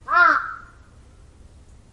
动物 " crow03
Tag: 鸟鸣声 森林 乌鸦 乌鸦 现场录音